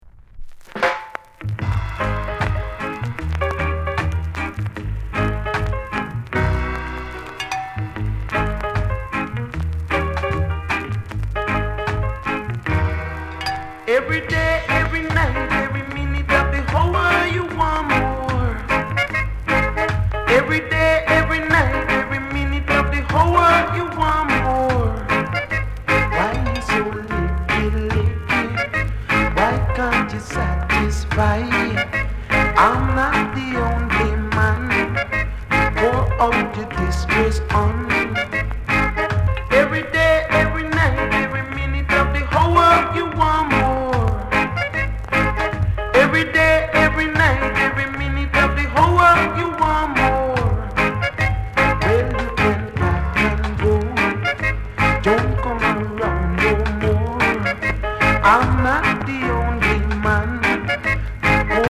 NICE ROCKSTEADY INST